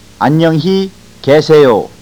Ahn Young He Ke Seh Yo- Good bye
ahn_young_hee_keh_se_yo.au